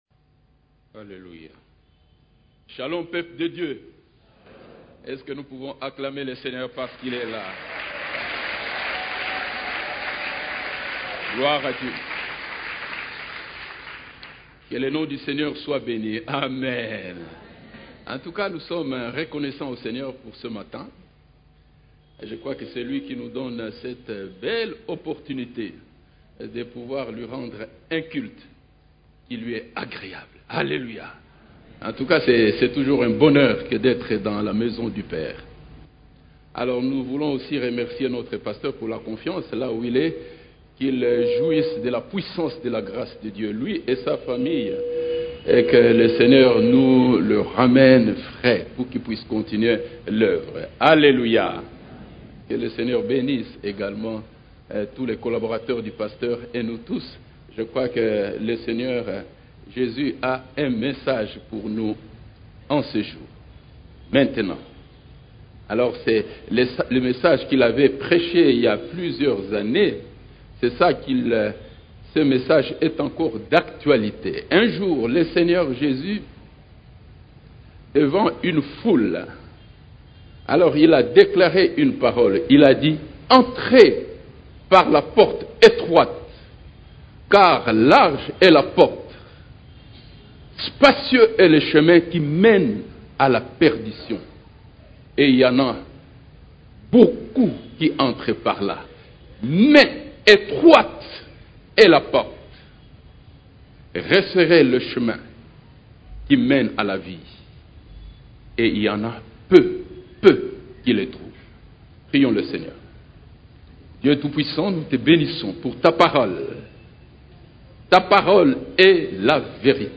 CEF la Borne, Culte du Dimanche, Sentinelle, que dis-tu de la nuit ?